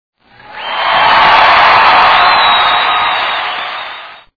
Sound Effect of the Week A screaming, cheering audience.
applause.mp3